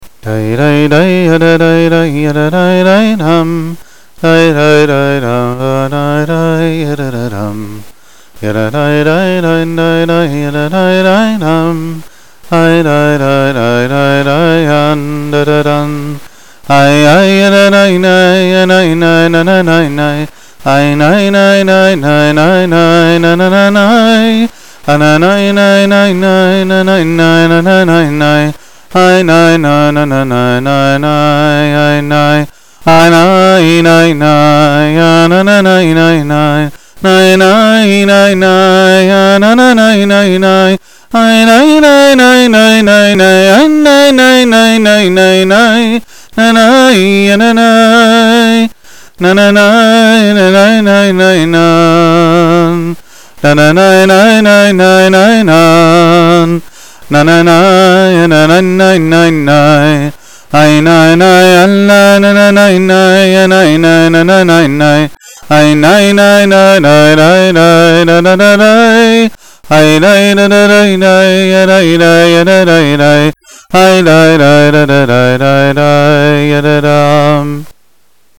The first nigun is an upbeat waltz, while the second is pure deveikes and sits much lower in the kishkes.
Waltz:
breslov waltz.mp3